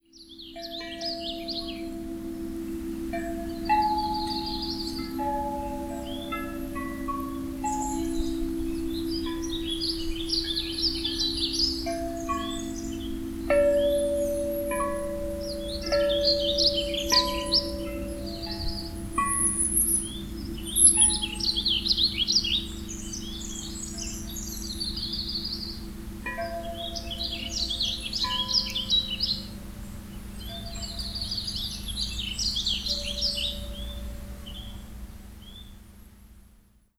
This short recording was captured using my cellphone. Besides the lovely wind chimes you can hear Warbling Vireo, Yellow-rumped Warbler, Chestnut-backed Chickadee, and Pacific Wren.
Kitty-Coleman-Labyrinth-Chimes.wav